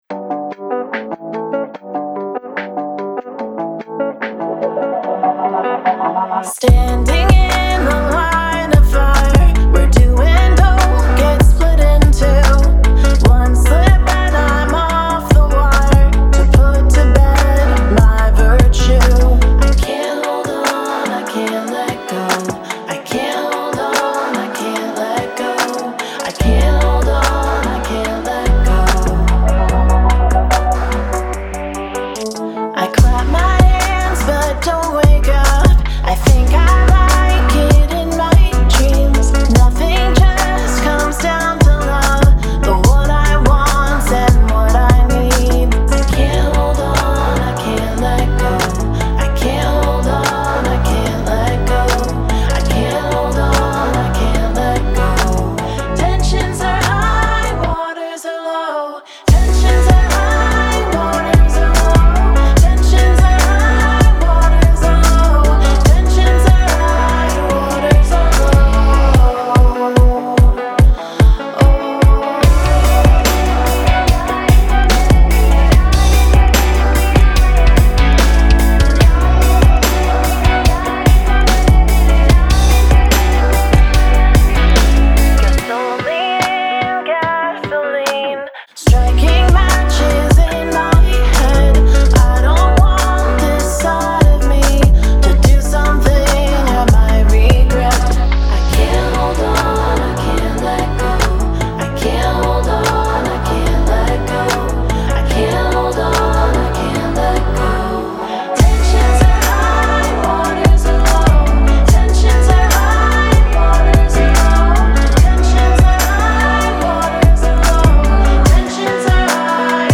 Синтезаторные "партии" тоже можно корректировать, развивать по желанию.
демо скетчем